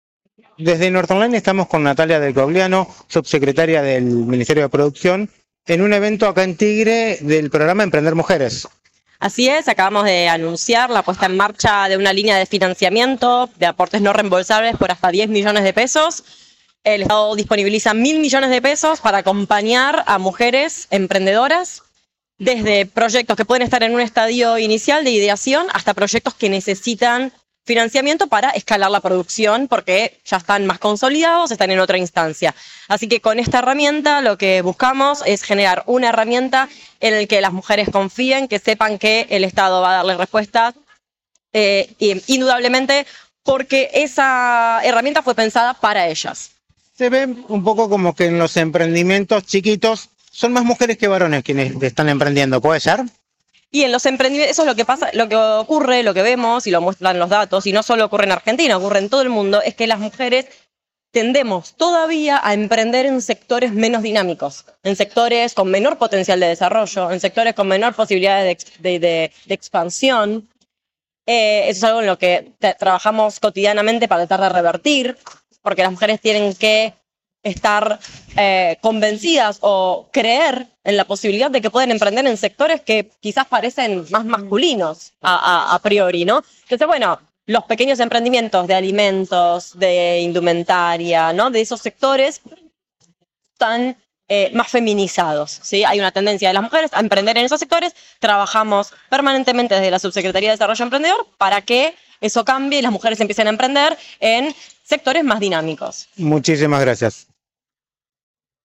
La subsecretaria de Desarrollo Emprendedor de la Nación conversó en exclusiva con Norte Online sobre el evento “Emprender y Producir en Igualdad”, que tuvo lugar en Tigre.